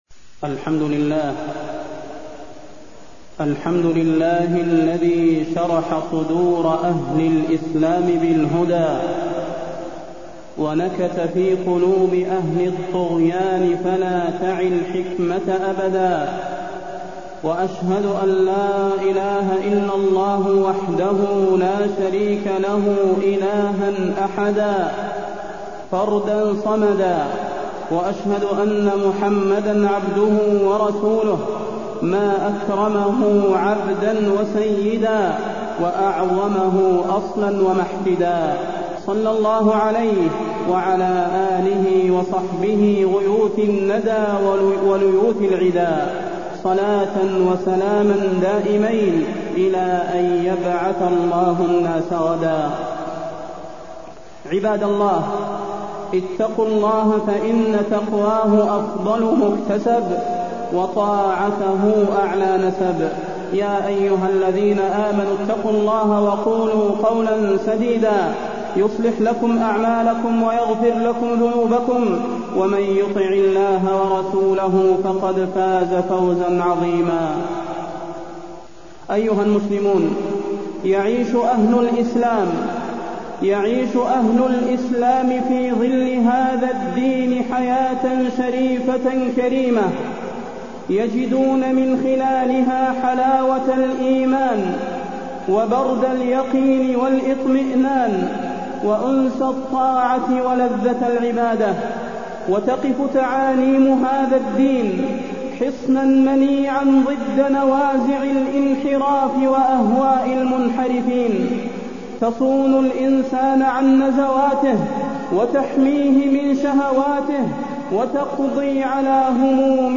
فضيلة الشيخ د. صلاح بن محمد البدير
تاريخ النشر ٨ ربيع الثاني ١٤٢٢ هـ المكان: المسجد النبوي الشيخ: فضيلة الشيخ د. صلاح بن محمد البدير فضيلة الشيخ د. صلاح بن محمد البدير تحريم الأغاني والمعازف